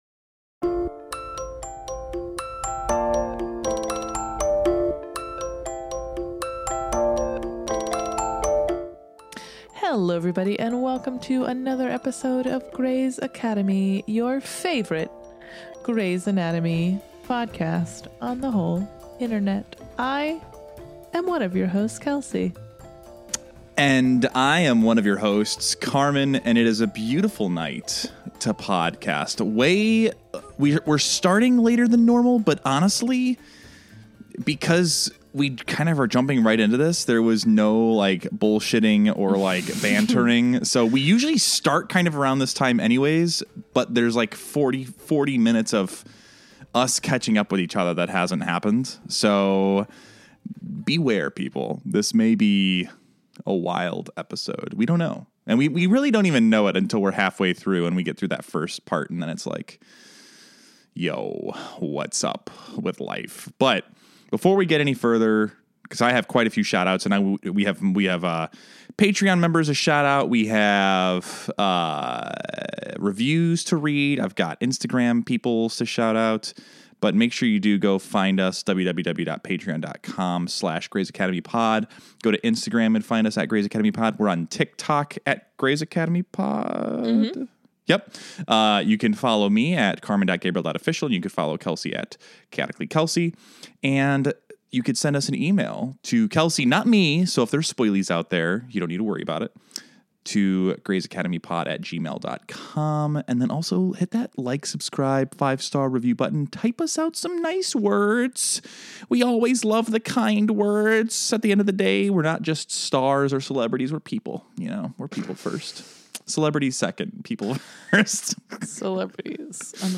FAIR WARNING: skip 20 minutes in if you hate the banter, shoutouts, or non-episode related content.